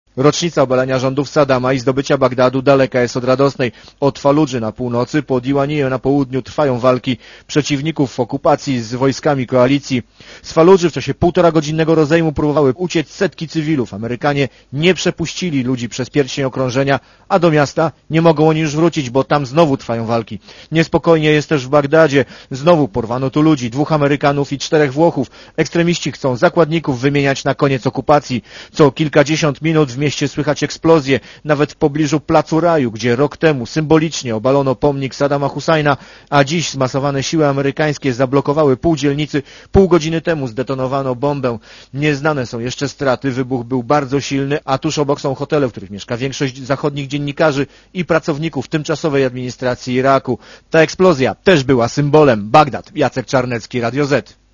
Z Iraku mówi